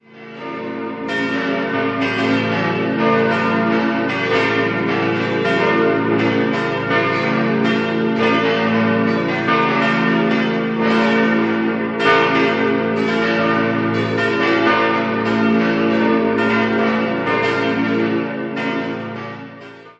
Die heute reformierte Jakobskirche liegt am Pilgerweg Konstanz-Einsiedeln und bekam 1489 durch den Ausbau von Turm und Schiff ihr heutiges Aussehen. Bei einer Innenrenovierung im Jahr 1975 wurden die wertvollen spätgotischen Fresken freigelegt. 5-stimmiges Geläut: b°-d'-f'-g'-a' Die Glocken wurden 1954 von der Gießerei Rüetschi in Aarau gegossen.